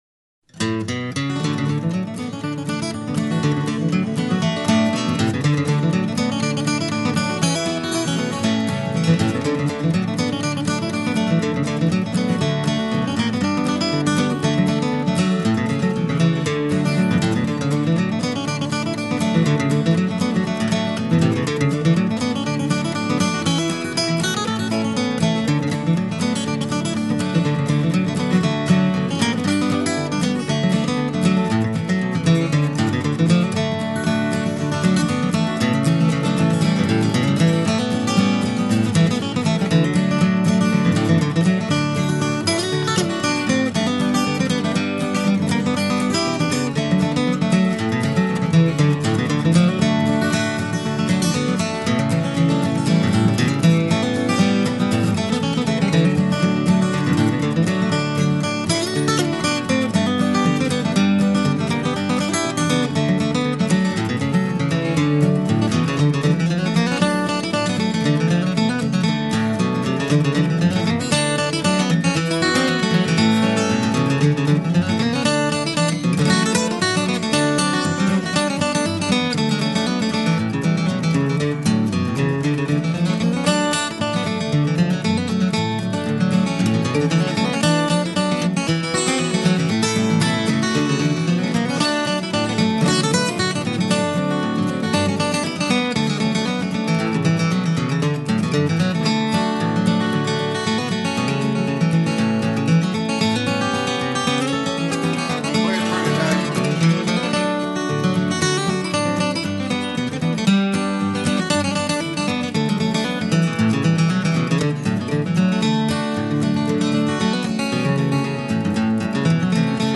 Bluegrass گیتار آگوستیک بیکلام